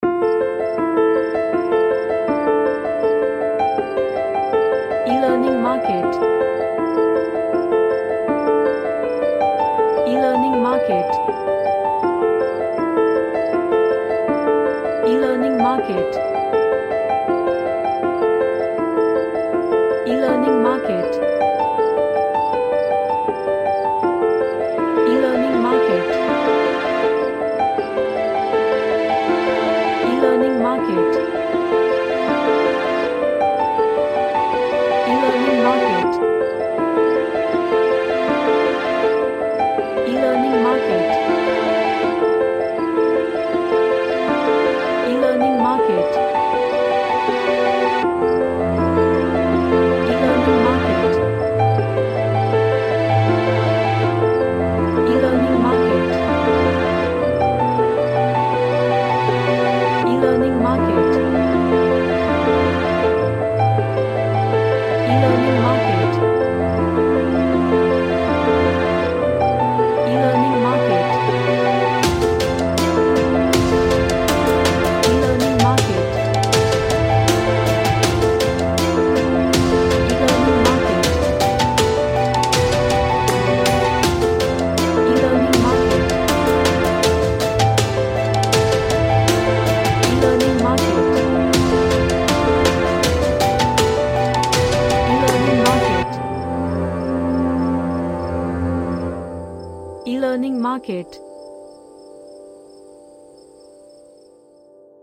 A suspencefull cinematic track
Suspense / Drama